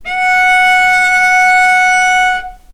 healing-soundscapes/Sound Banks/HSS_OP_Pack/Strings/cello/ord/vc-F#5-mf.AIF at bf8b0d83acd083cad68aa8590bc4568aa0baec05
vc-F#5-mf.AIF